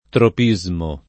[ trop &@ mo ]